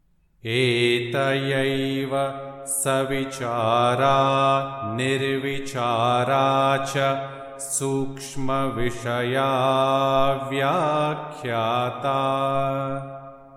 Yoga Sutra 1.44 | Etayaiva savichārā nirvichārā cha sūkṣ...| Chant Sutra 1.44